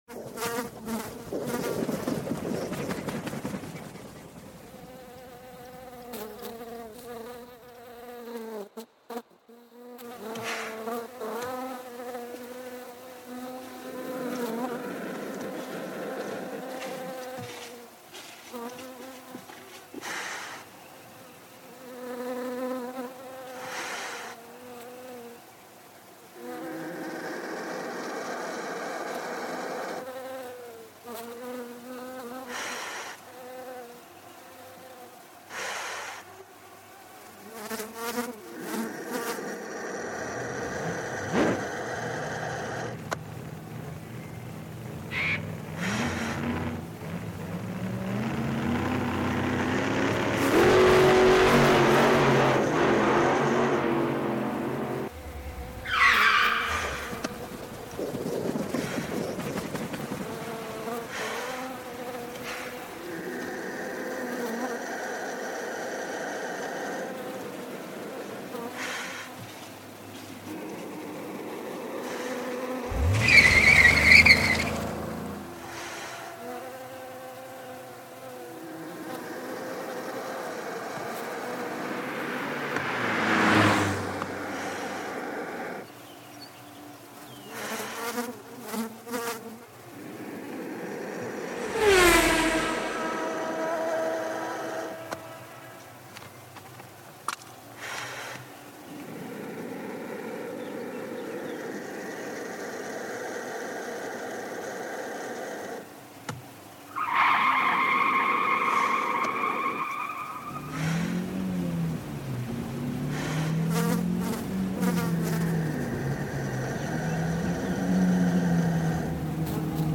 Shed Gallery, California State University, Northridge.